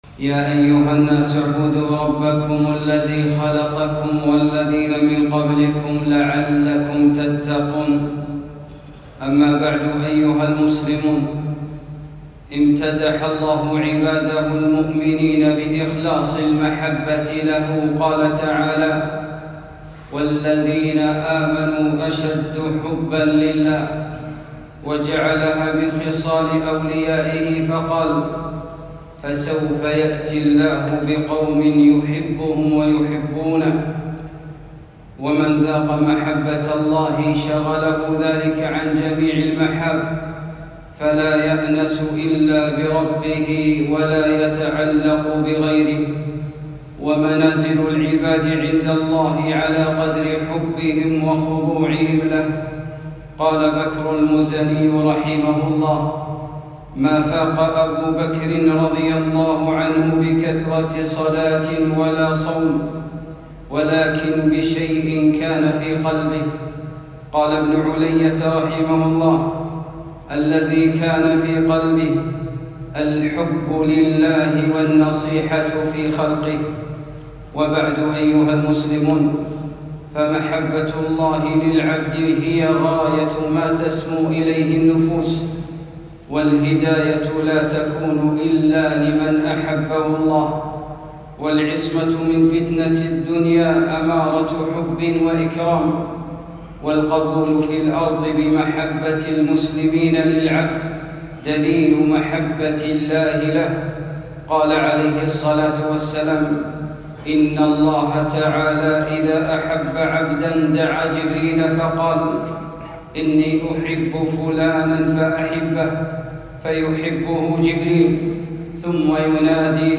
المحبة الصادقة - خطبة